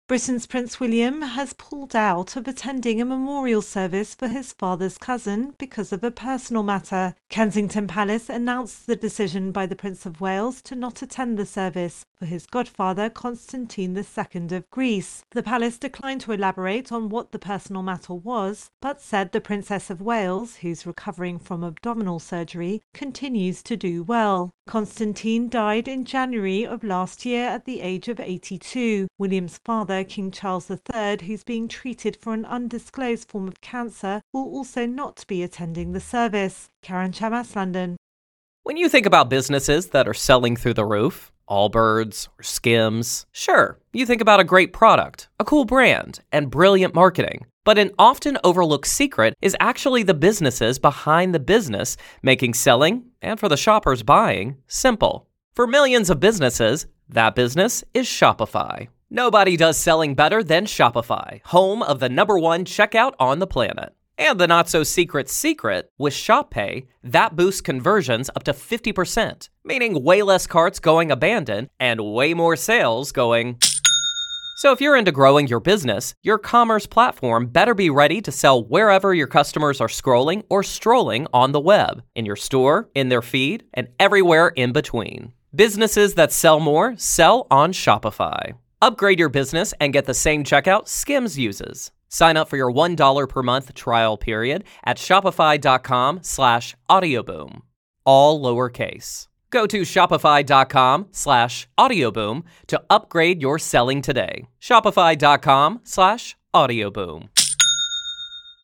AP correspondent reports on Britain's Prince William.